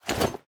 Minecraft Version Minecraft Version 25w18a Latest Release | Latest Snapshot 25w18a / assets / minecraft / sounds / item / armor / equip_iron1.ogg Compare With Compare With Latest Release | Latest Snapshot
equip_iron1.ogg